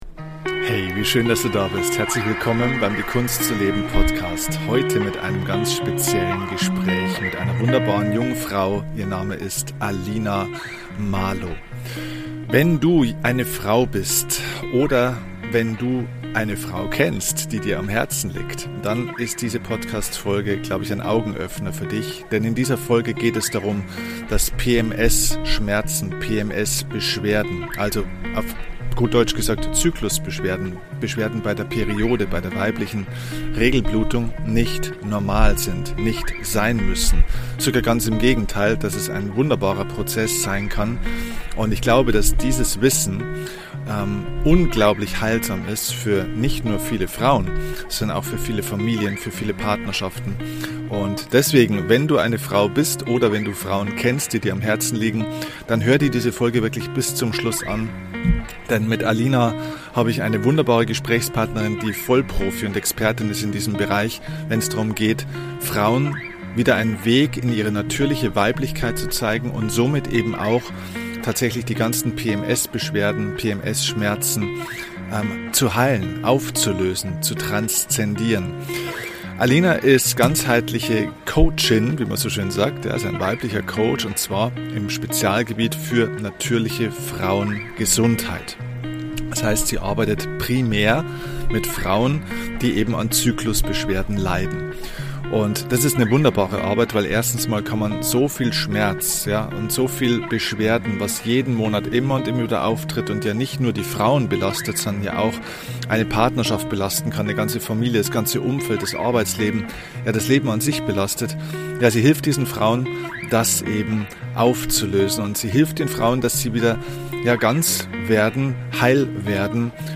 #497 PMS-Probleme langfristig heilen – Interview